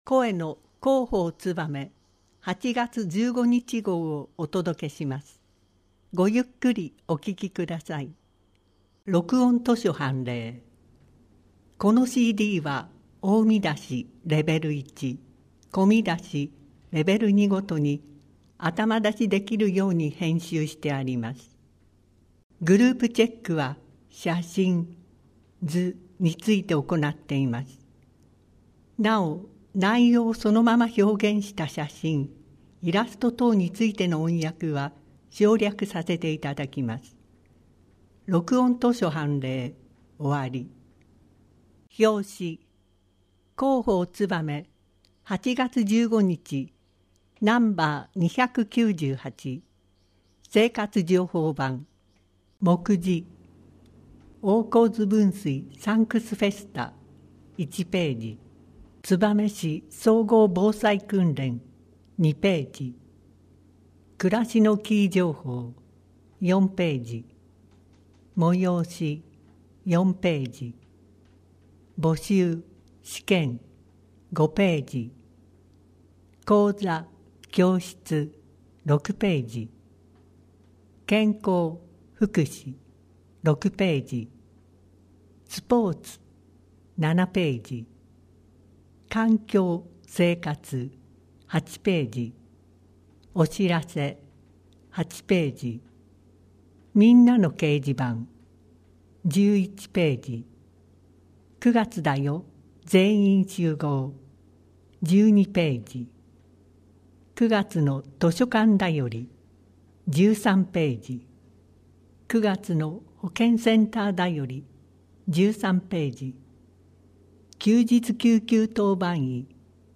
声の広報つばめ2018年8月15日号